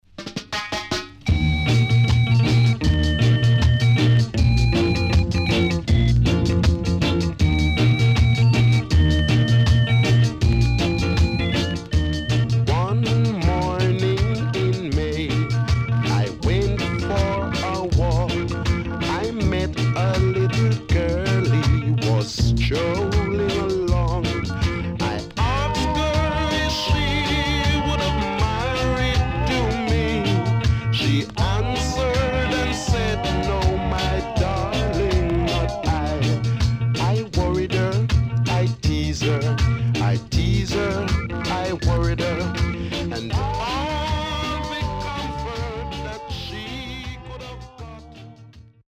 HOME > Back Order [VINTAGE 7inch]  >  EARLY REGGAE
Good Early Reggae
SIDE A:所々チリノイズ入ります。